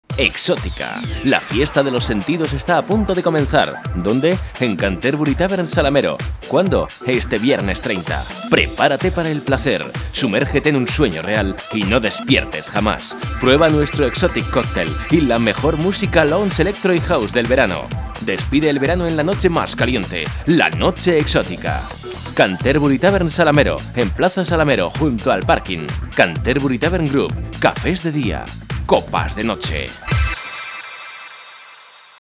Sprecher spanisch für Werbung, Industrie, Imagefilme, E-Learning etc
Sprechprobe: Sonstiges (Muttersprache):
spanish voice over talent